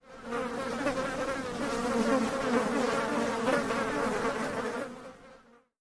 Spores_Sound.wav